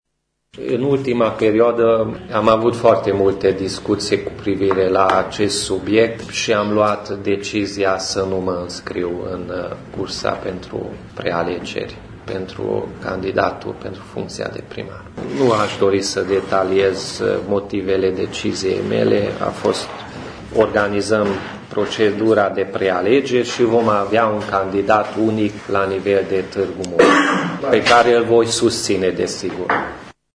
Declarația a fost făcută astăzi, într-o conferință de presă, cu prilejul anunțării campaniei de prealegeri din interiorul UDMR Tîrgu-Mureș.
Liderul UDMR Tîrgu-Mureș, Peti Andras: